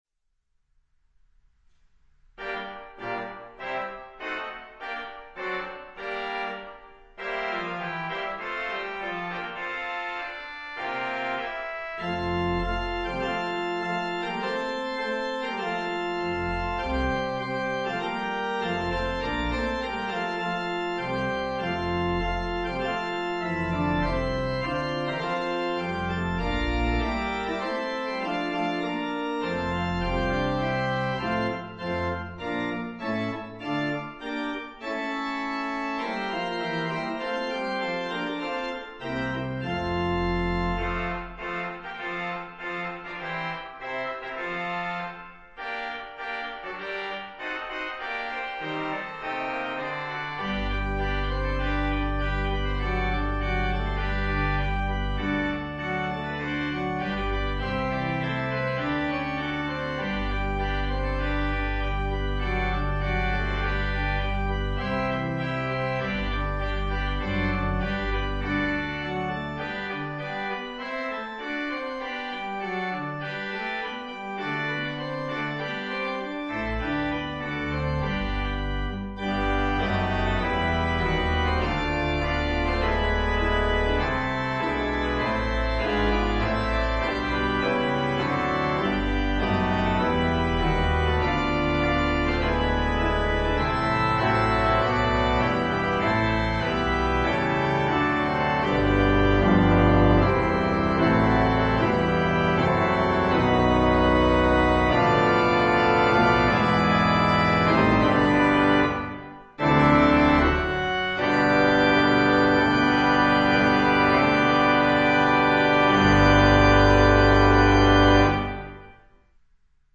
Perfect for a postlude.